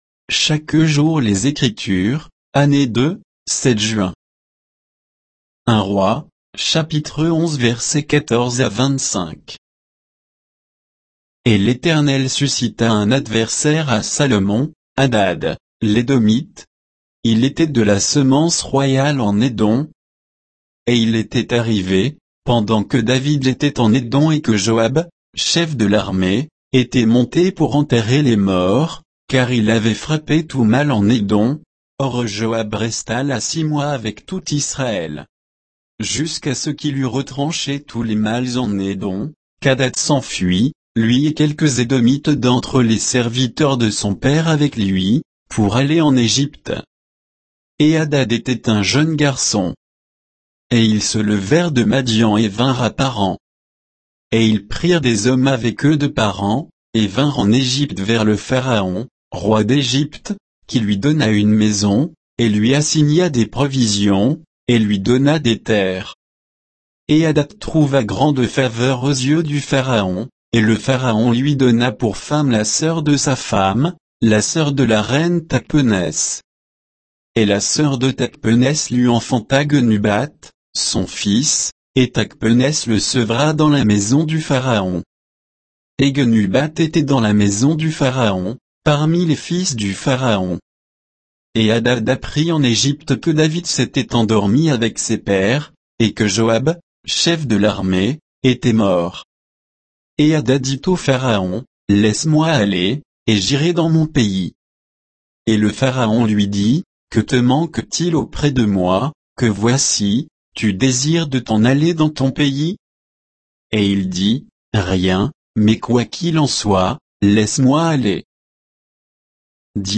Méditation quoditienne de Chaque jour les Écritures sur 1 Rois 11